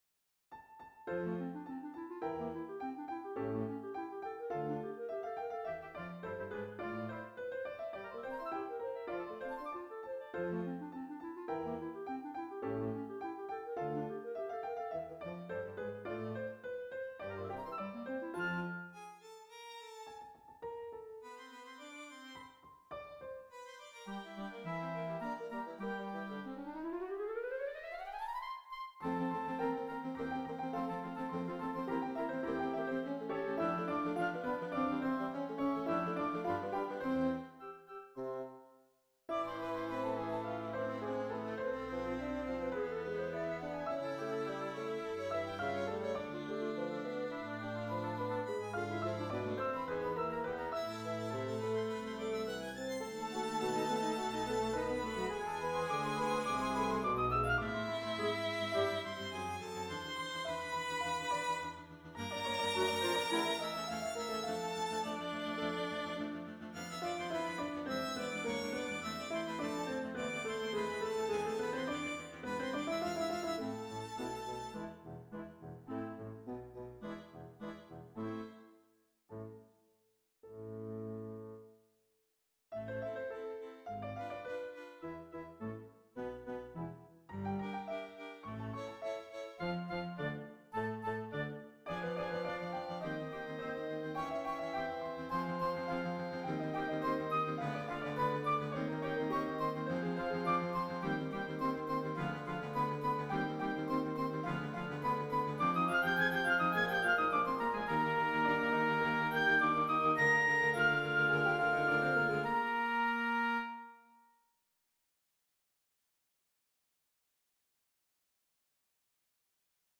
(F-Dur)